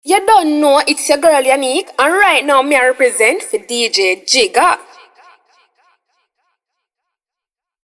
Get your custom DJ drops recorded by a young, energetic and sexy Jamaican girl today.
Jamaican Female DJ Drops
Stand out with our personalized female DJ drops, professionally recorded by a Jamaican voice artist. Authentic accent.